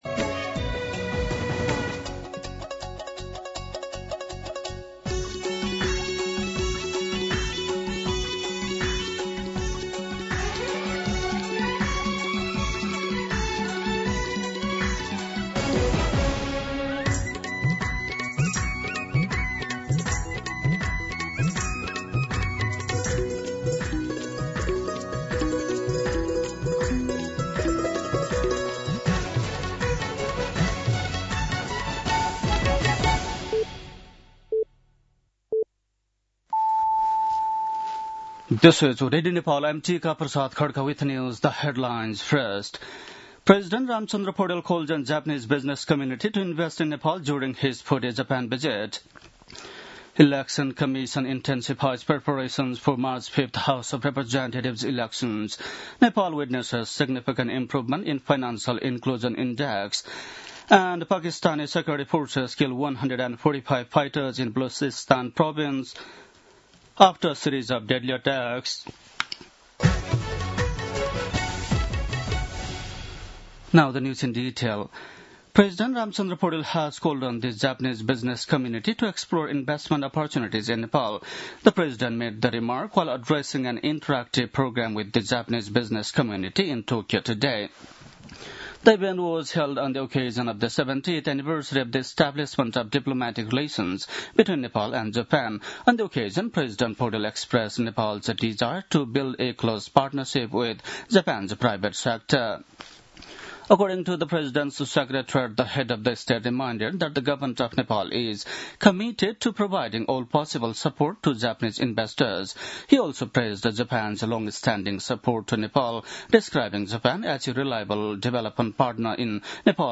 दिउँसो २ बजेको अङ्ग्रेजी समाचार : १९ माघ , २०८२
2pm-English-News-19.mp3